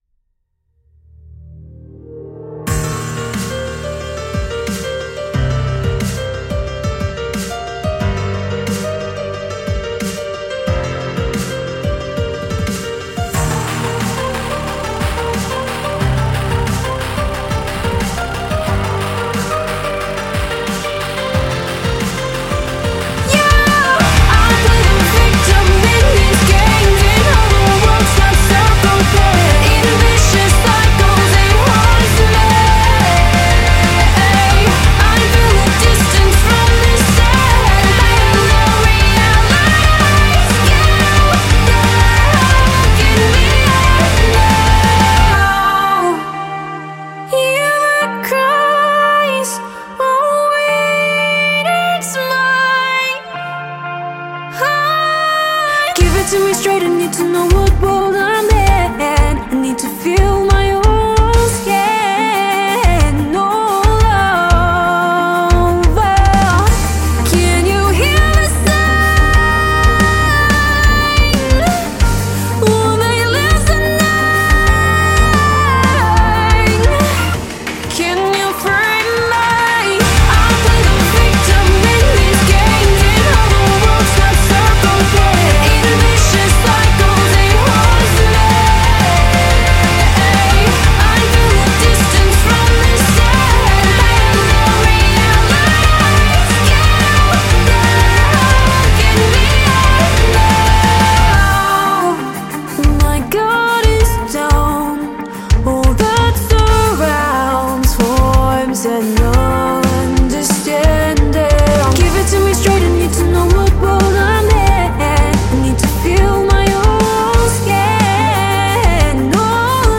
# Rock